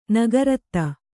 ♪ nagarattta